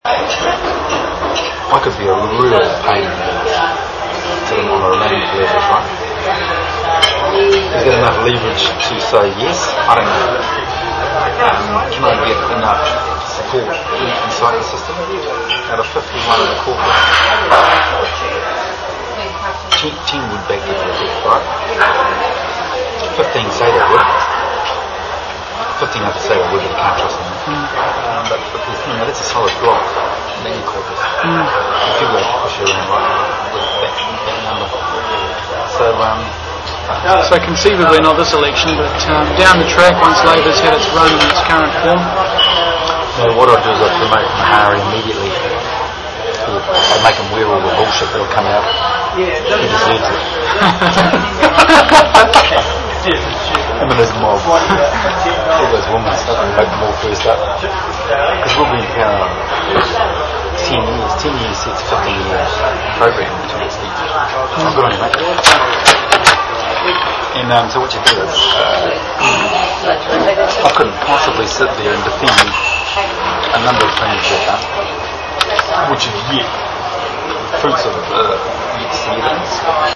The interview was conducted at an Auckland cafe, and no request was made by either party for it to be off-the-record.